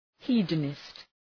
{‘hi:də,nıst}
hedonist.mp3